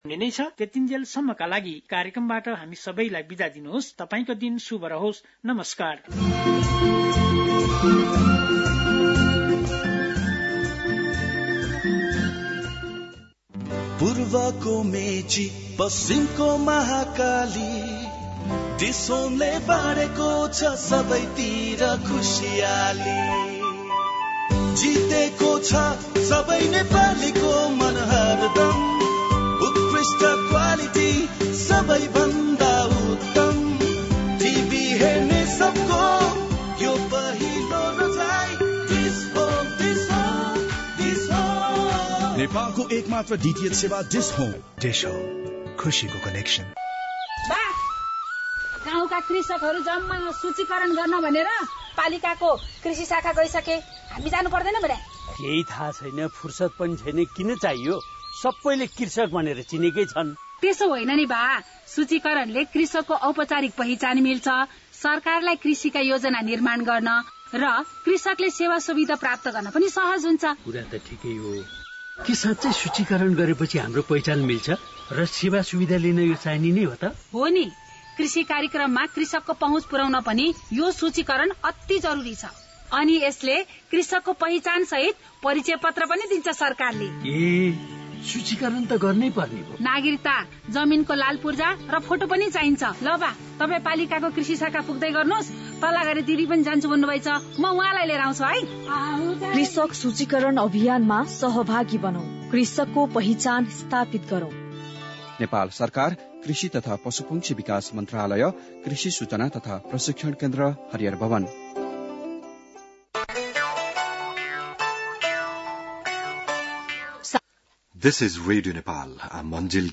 बिहान ८ बजेको अङ्ग्रेजी समाचार : २ चैत , २०८१